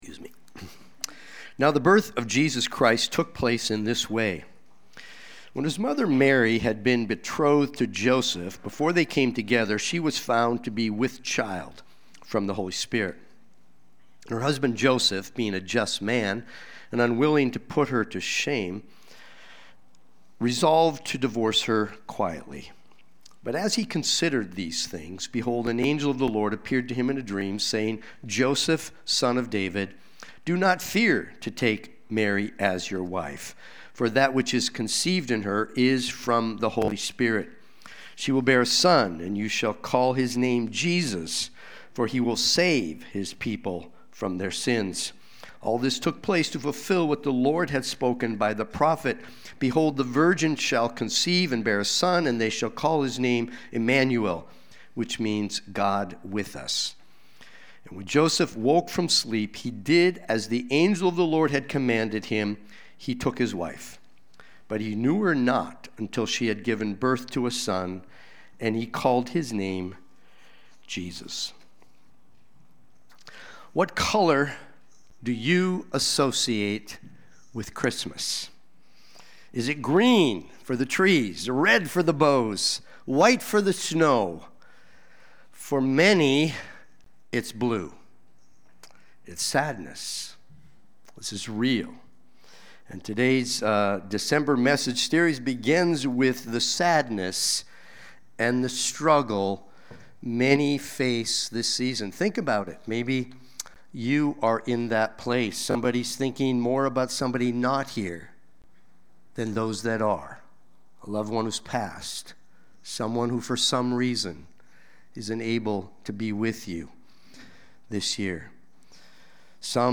Watch the replay or listen to the sermon.
Sunday-Worship-main-12725.mp3